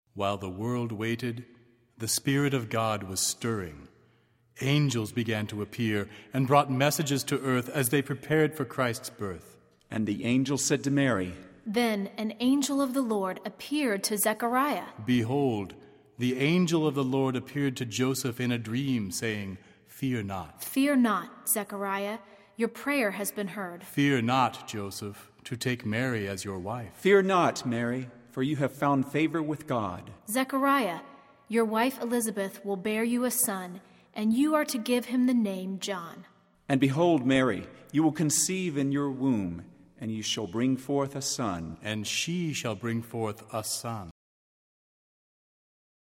Voicing: Instrumental Parts